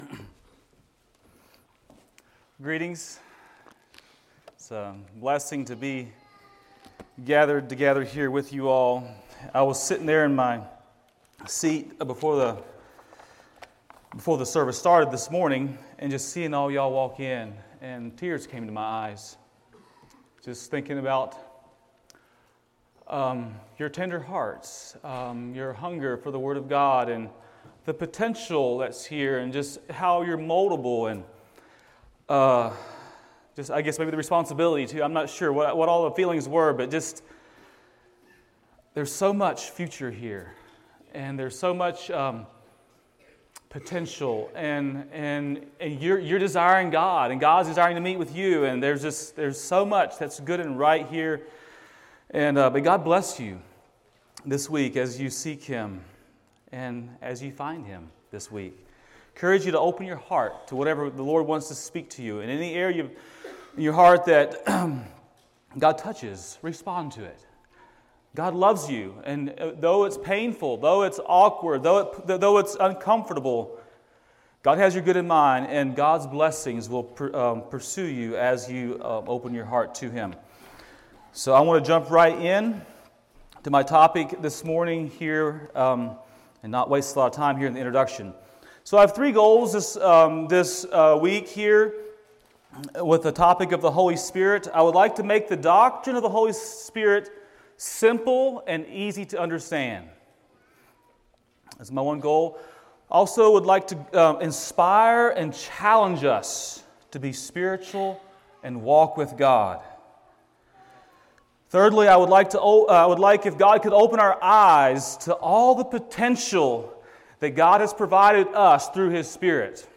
A message from the series "Bible Boot Camp 2025."